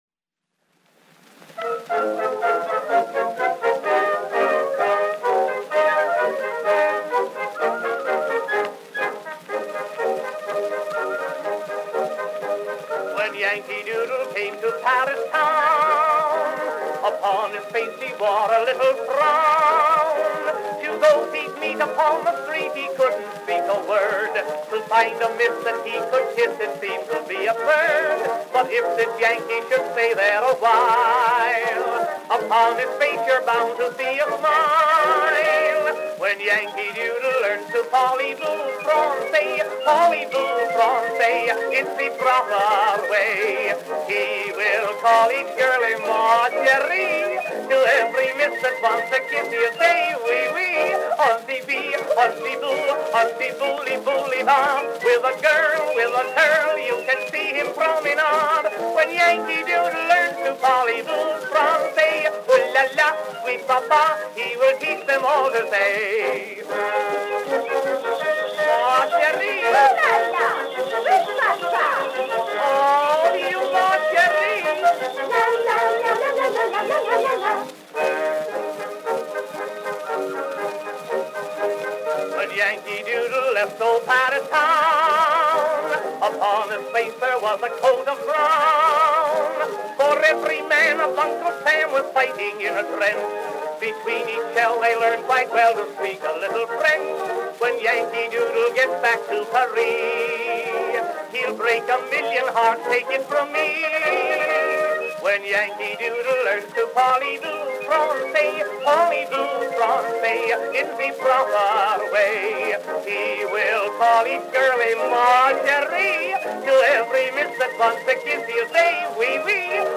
Our collection is comprised of wax cylinder donations from many sources, and with the digitization process being fully automated, not all listed contents have been confirmed.